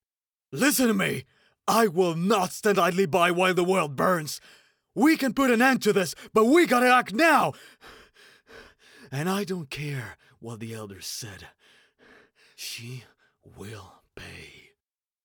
落ち着いた／穏やか
知的／クール
収録　　宅録、
Voice Actor Sample7（怒っている軍人）[↓DOWNLOAD]